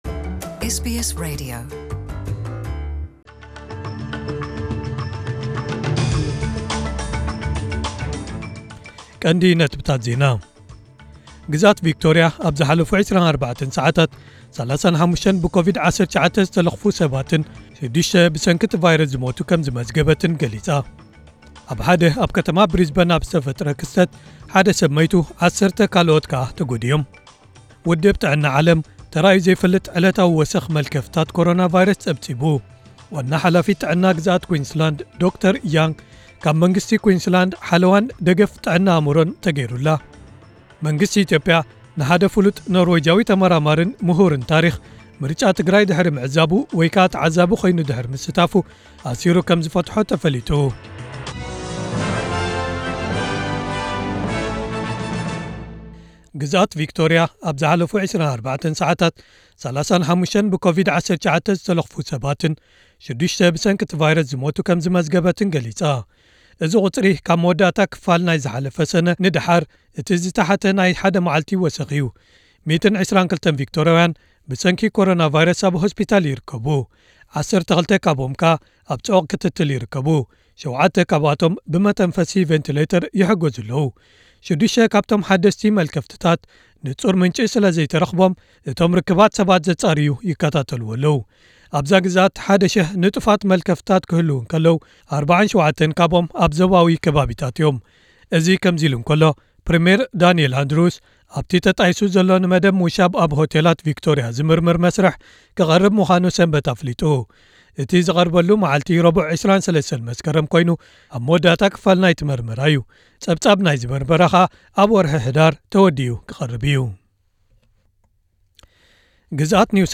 ዕለታዊ ዜና ኤስቢኤስ ትግርኛ (14/09/2020)